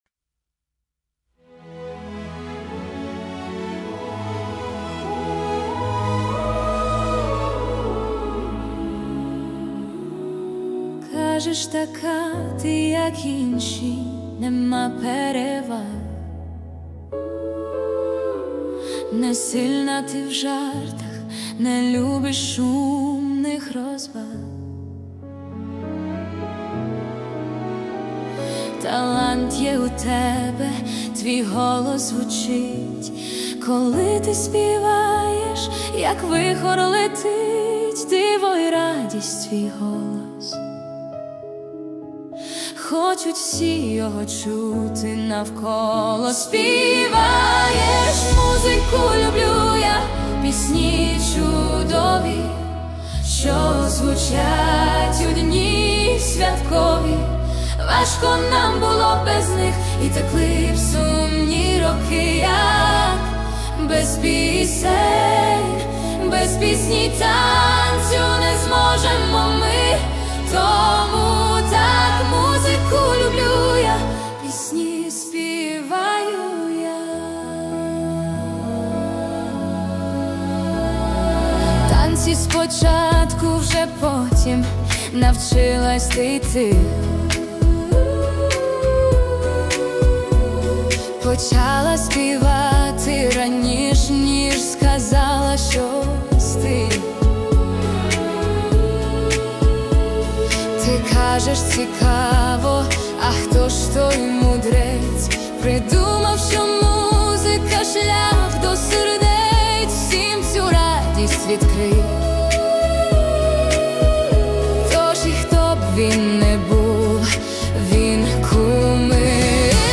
Український кавер